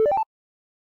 8bit Menu Select
Short chiptune sound to represent the selection of an option on a menu, or any kind of successful action.
Art Type: Sound Effect
vgmenuselect_0.ogg